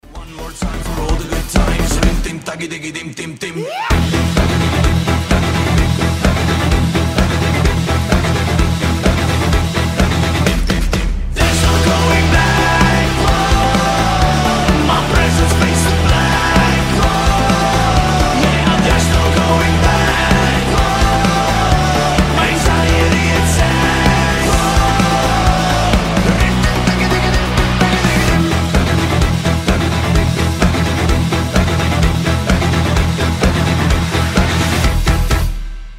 прикольные , рок , евровидение , веселые